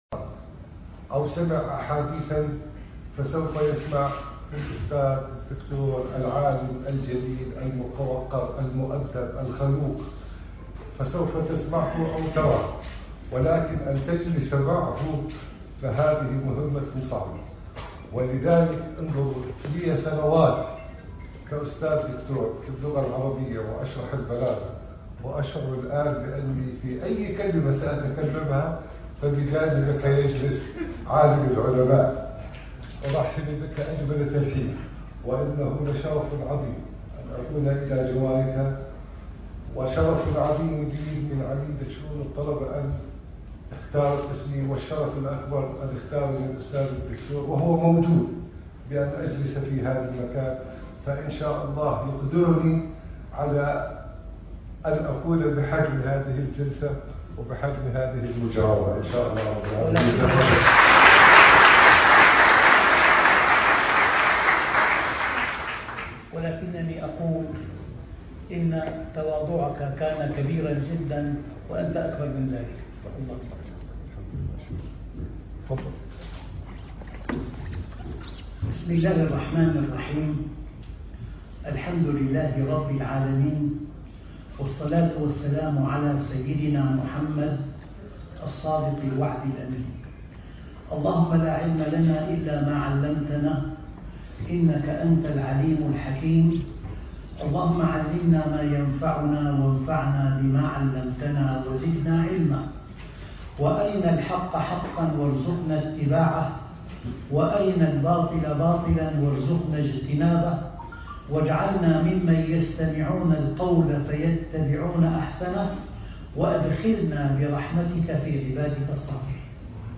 ندوات مختلفة - الأردن - عمان - الجامعة الألمانية الأردنية المحاضرة 83 - الحياة الطيبة - الشيخ محمد راتب النابلسي